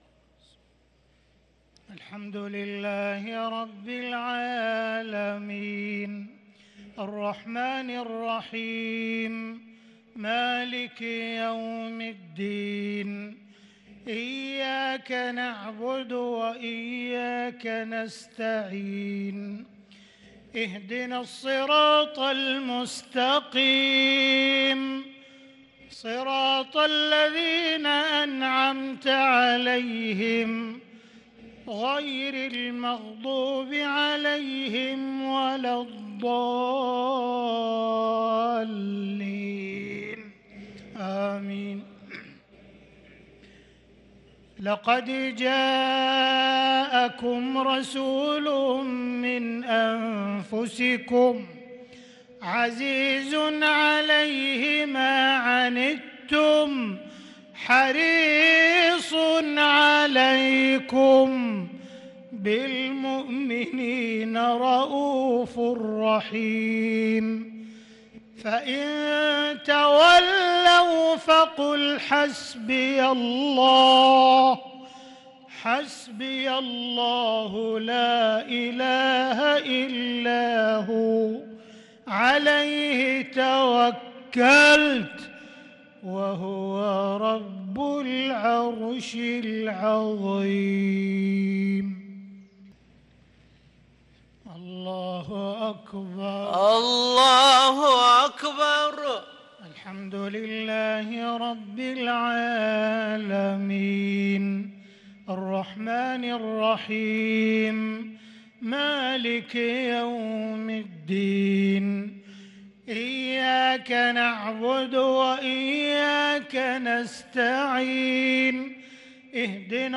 صلاة المغرب للقارئ عبدالرحمن السديس 27 رمضان 1443 هـ
تِلَاوَات الْحَرَمَيْن .